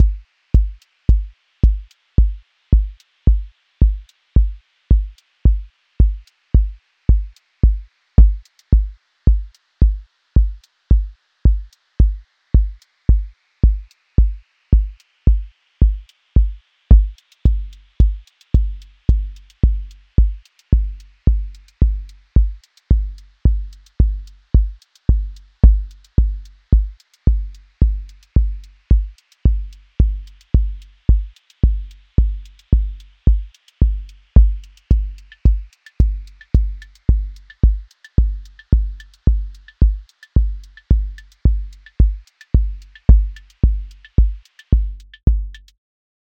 QA Listening Test house Template: four_on_floor
Three detuned sine clusters built with Klang — a low sub-drone around 55Hz, a mid voice around 220Hz, a high shimmer around 880Hz. Each cluster stacks 5-7 sines with ±2-8Hz detuning. The beating frequencies between partials create the pulse — no explicit rhythmic events. Slow amplitude crossfades between voices across the timeline so the density breathes. No kick, no snare, no grid.